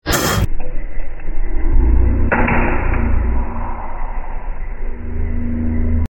Blowing Paper In Slow Motion Sound Effects Free Download